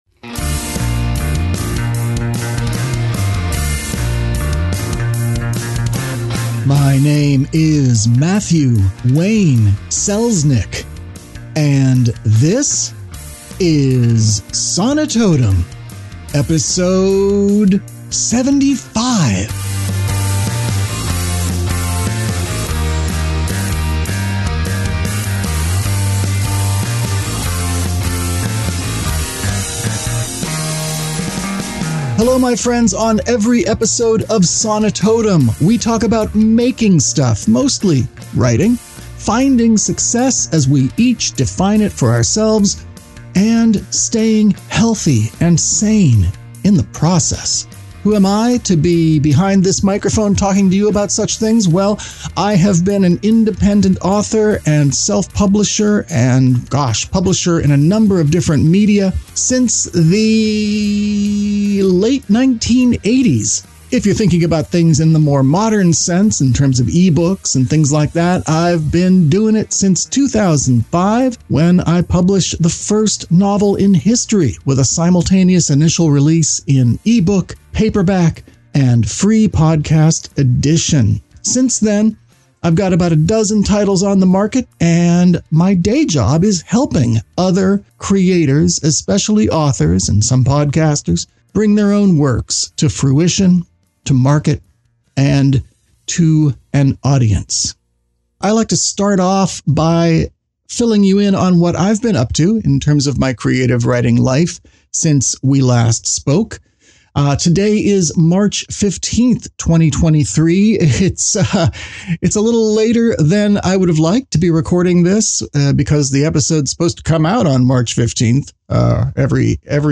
In Conversation
interview